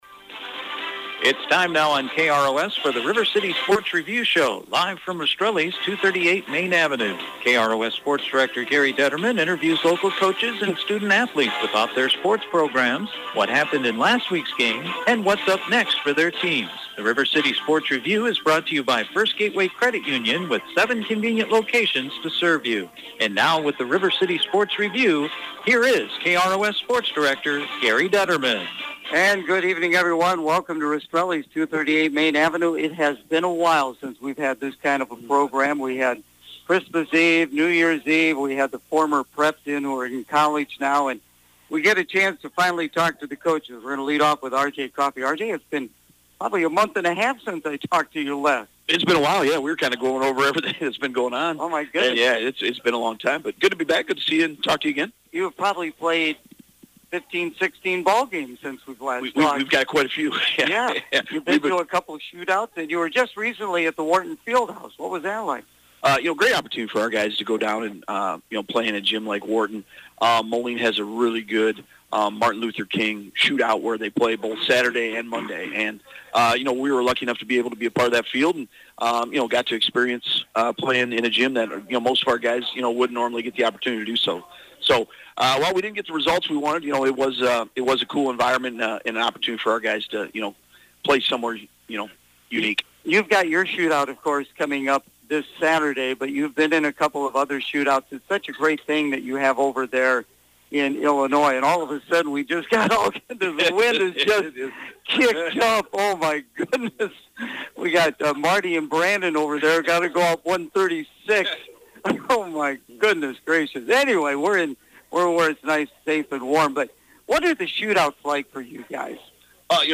The River City Sports Review Show on Wednesday night from Rastrelli’s Restaurant